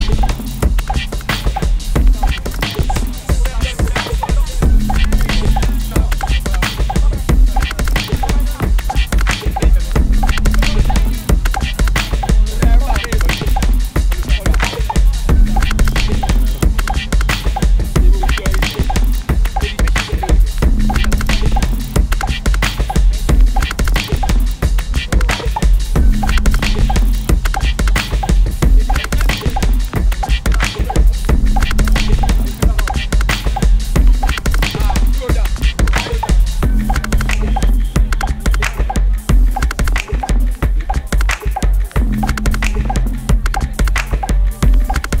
musica elettronica